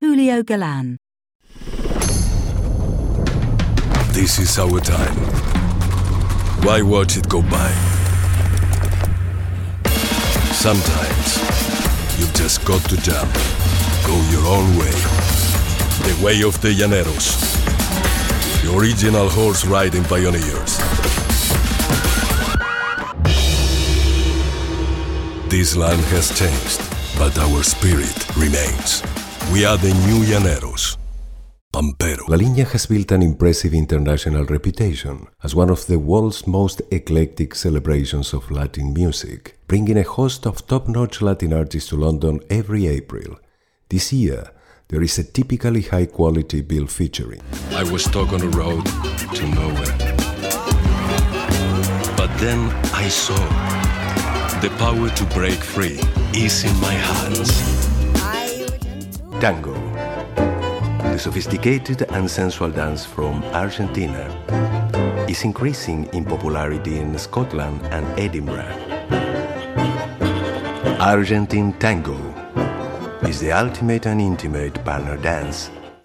Argentinian Age range: 30s - 50s Showreel 0:00 / 0:00